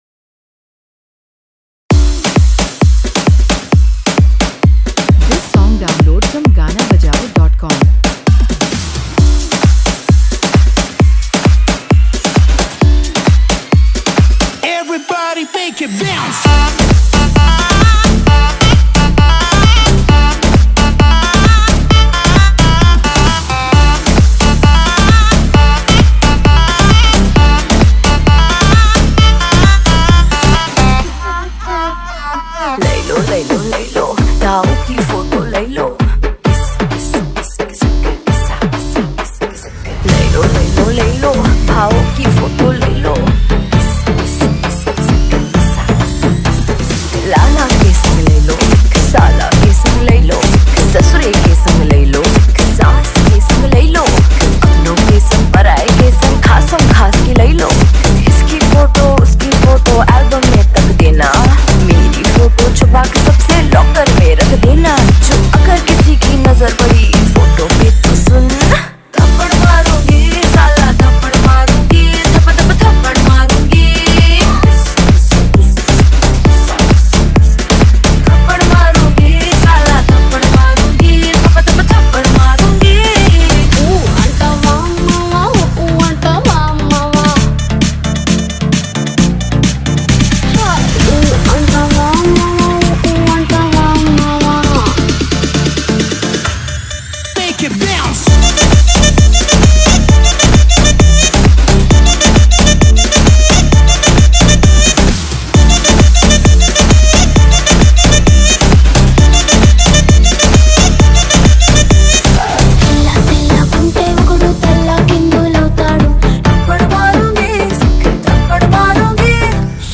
Latest Item Song
# Hindi Remix Audio Song Mp3 Download